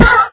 minecraft / sounds / mob / horse / hit1.ogg